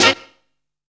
TOOTHORN.wav